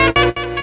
HORN STUT05L.wav